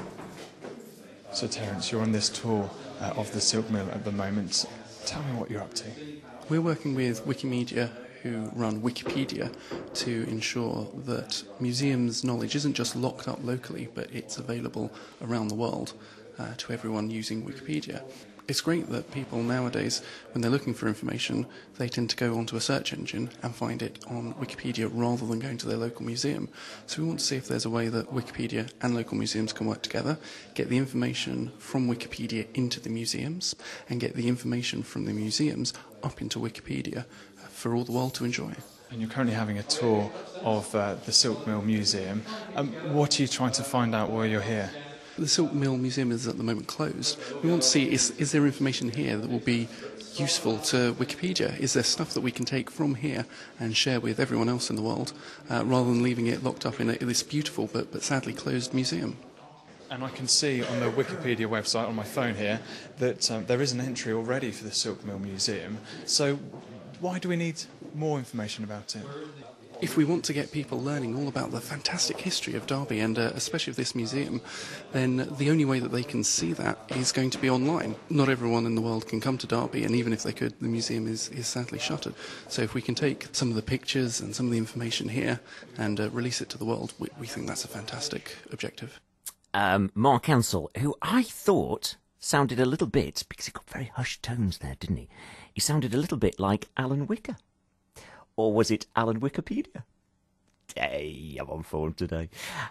Derby Silk Mill Interview